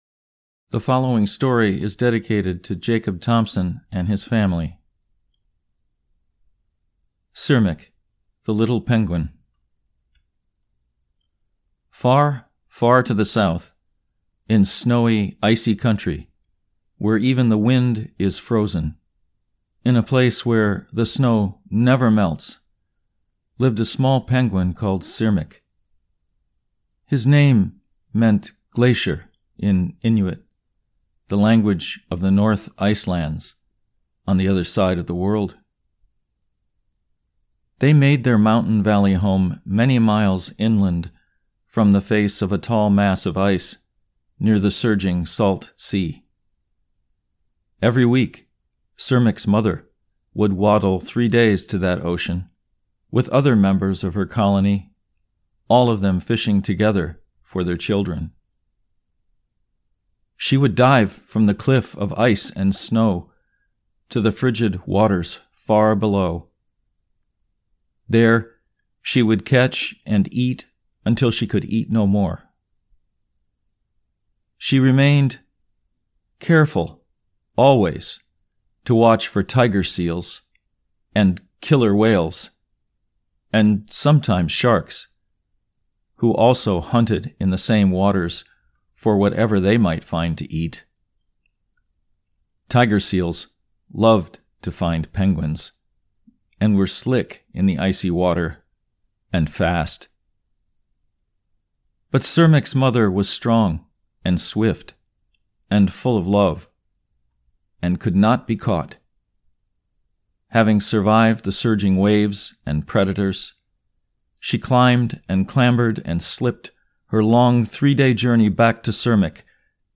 Sirmiq The Little Penguin (Recitation)